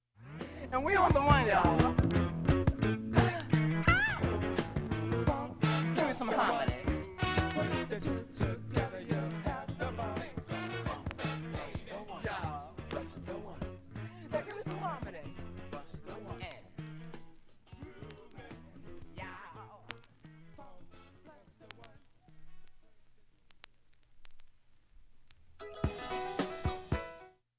盤面きれいで音質良好全曲試聴済み。
A-2終りフェイドアウト部に
かすかなプツが２回と１０回出ます。
６回までのかすかなプツが１箇所
３回までのかすかなプツが２箇所
単発のかすかなプツが３箇所
元メンバー達からなるP-Funkバンド、